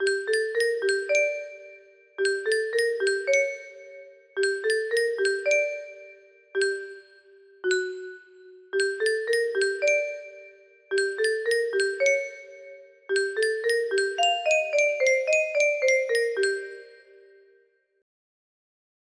dmo music box melody